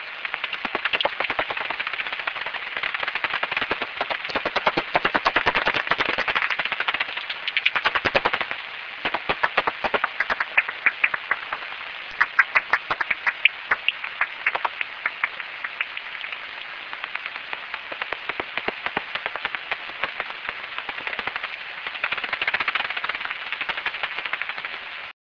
En vol, la pipistrelle commune émet une succession irrégulière de cris d'écholocation en fréquence modulée aplatie aux alentours de 45 kHz, ce qui donne à cette fréquence au détecteur ultrasonique de chauves-souris des bruits "mouillés" comme vous pouvez l'entendre sur ces deux enregistrements:
enregistrement des cris d'écholocation d'une pipistrelle commune (Pettersson D200 réglé sur 45 kHz - 04/09/2008 - Watermael-Boitsfort, Belgique)